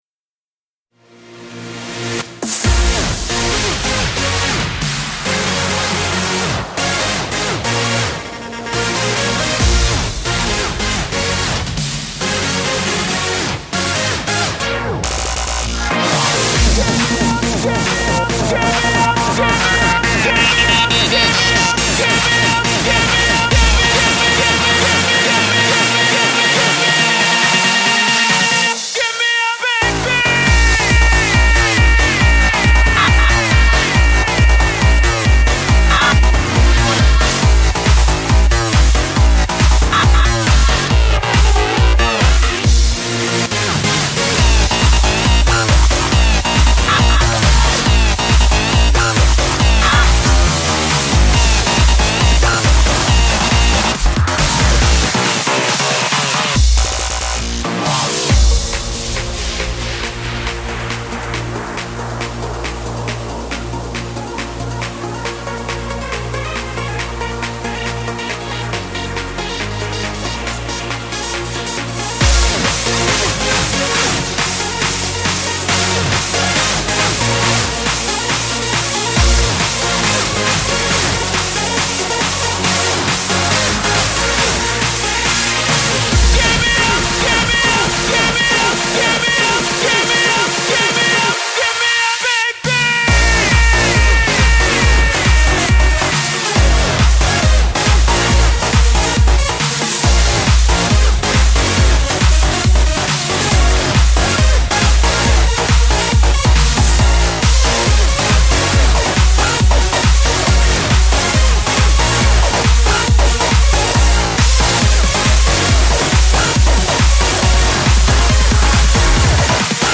BPM138
Audio QualityPerfect (Low Quality)